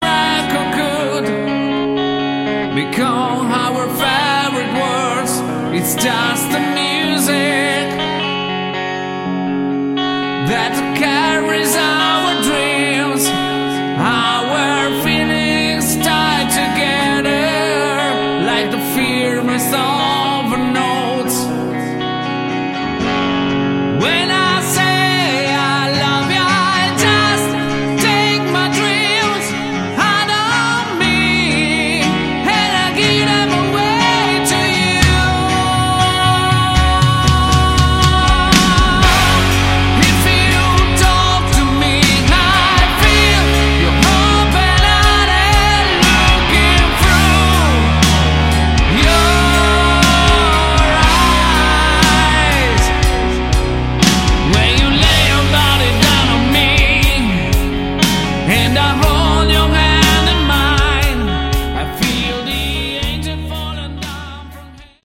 Category: Sleaze Glam
Vocals
Bass, Backing Vocals
Guitar
Drums, Backing Vocals